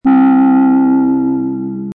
Weird Alien Sound Button - Free Download & Play
The Weird Alien sound button is a popular audio clip perfect for your soundboard, content creation, and entertainment.